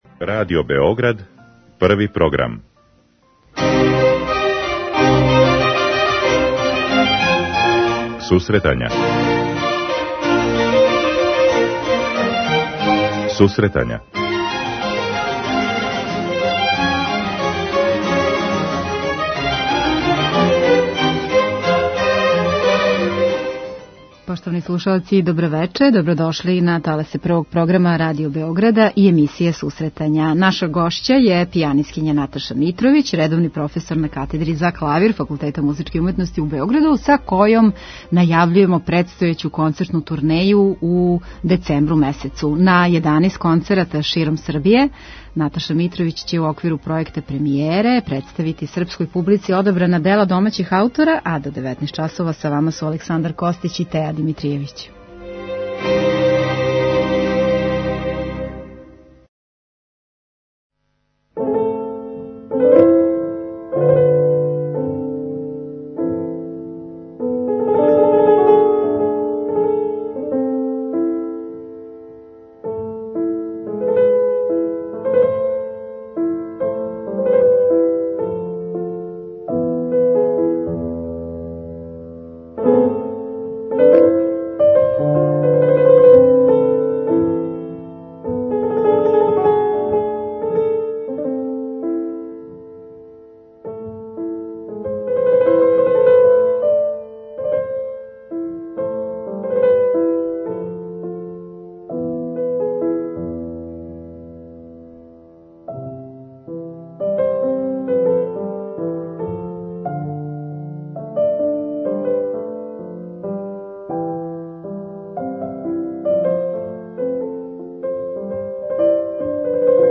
Музичка редакција Емисија за оне који воле уметничку музику.